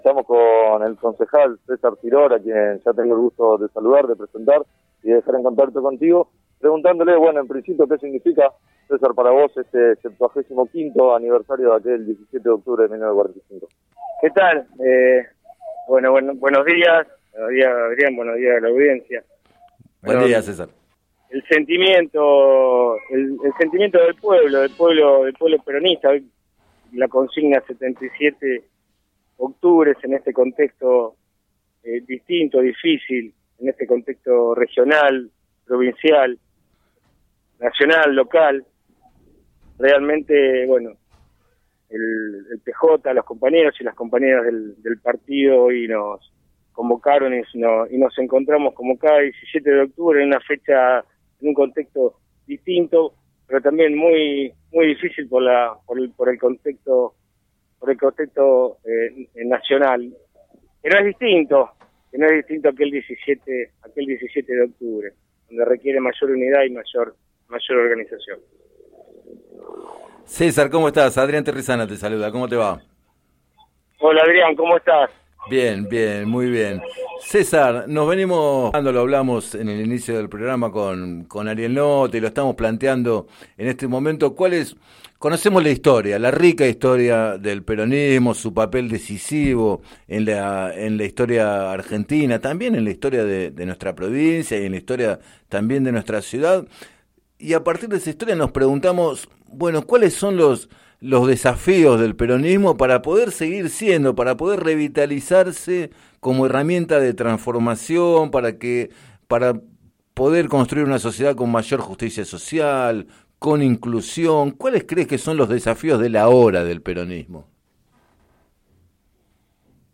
Consultado por Radio Líder 97.7 sobre el ámbito local, Siror llamó a “respetar el programa por el que se logró la unidad antes de las elecciones primarias de 2019 y gobernar de acuerdo a ese compromiso político y movimientista”.